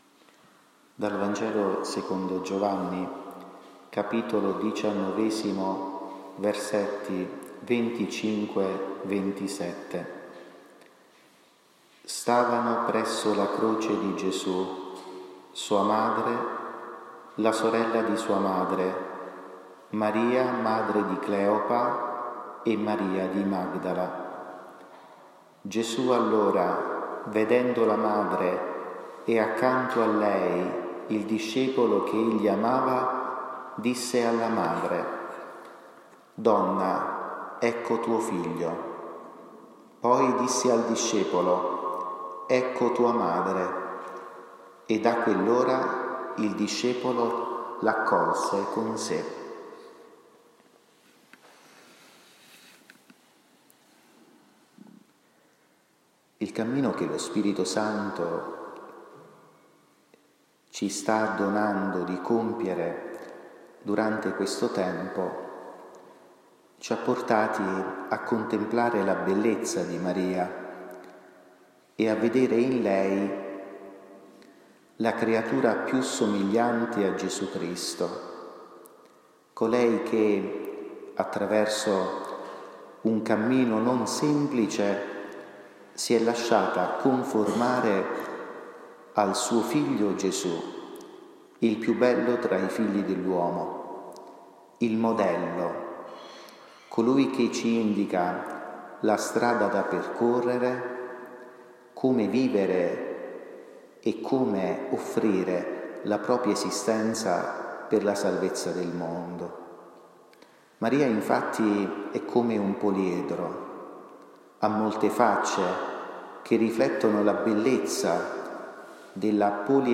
Catechesi mariana 30 maggio 2023.
Santuario Incoronata Montoro.